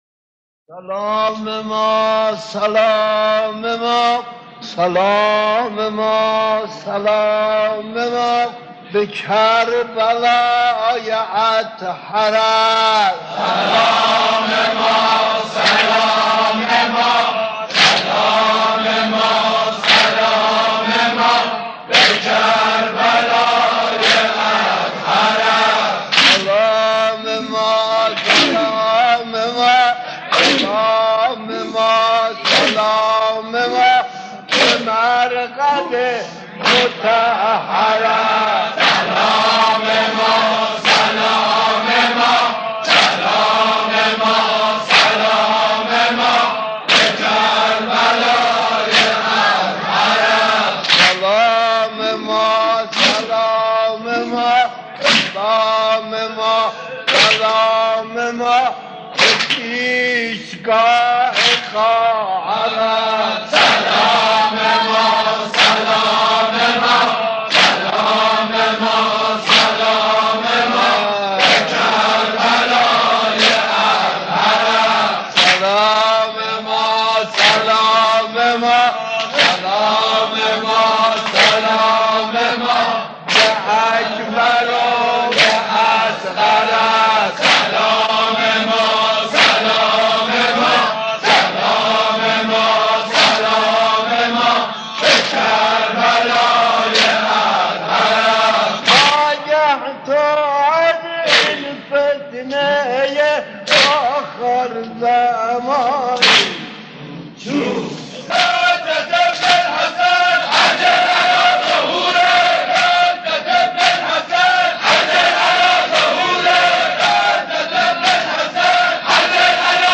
در پرده عشاق، صدای مداحان و مرثیه‌خوانان گذشته تهران قدیم را خواهید شنید که صدا و نفس‌شان شایسته ارتباط دادن مُحب و مَحبوب بوده است.
سلام ما سلام ما به کربلای اطهرت؛ ذکر سلام پایانی خطاب به سیدالشهداء (ع)؛ واحد خوانی با یادی از امام زمان (عج)